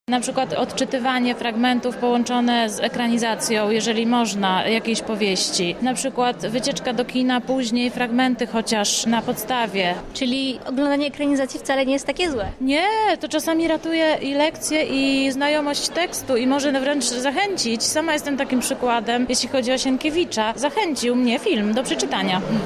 Dziś odbyła się konferencja „Upowszechnianie czytelnictwa i rozwój kompetencji czytelniczych”.